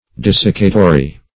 Desiccatory \De*sic"ca*to*ry\, a.
desiccatory.mp3